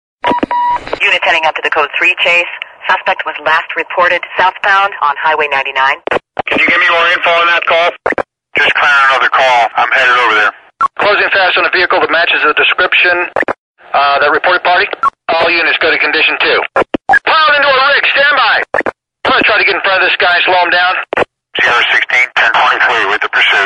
Звуки рации
Шум рации американских полицейских